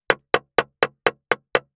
465,厨房,台所,野菜切る,咀嚼音,ナイフ,
効果音厨房/台所/レストラン/kitchen食器食材
効果音